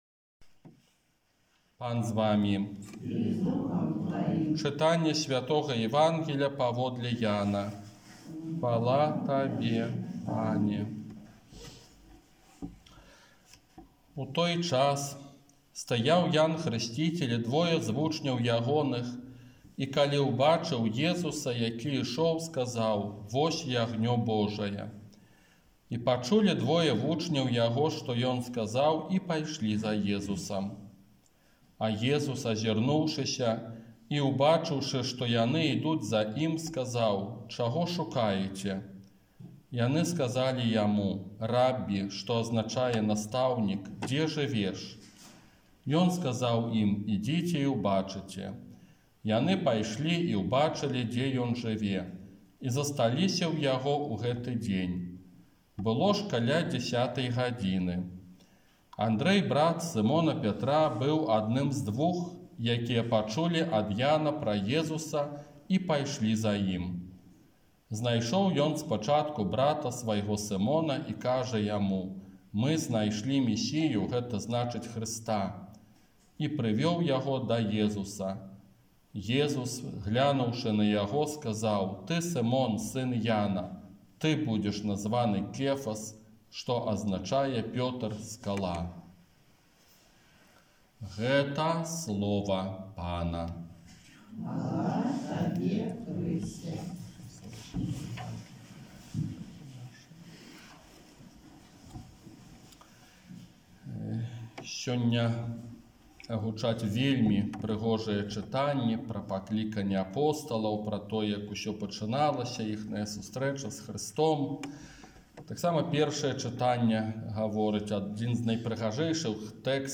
Казанне на другую звычайную нядзелю